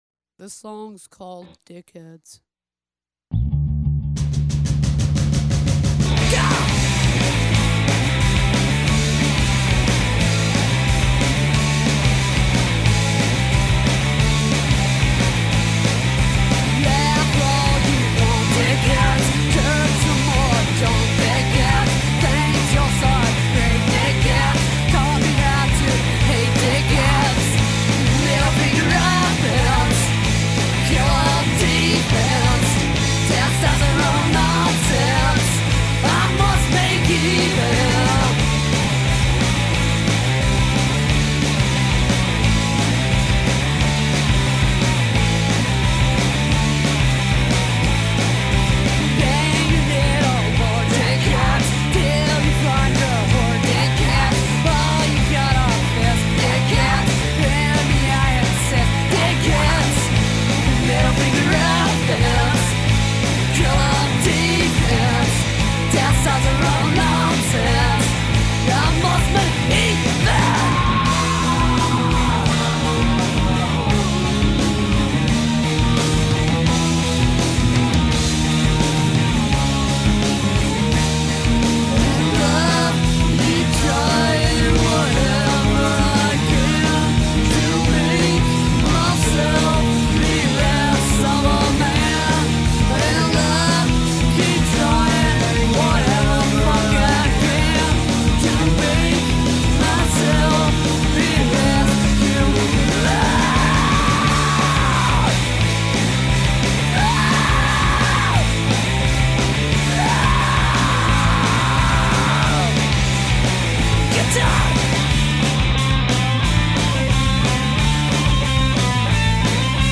guitar, vocal
bass, back-up vocal
drums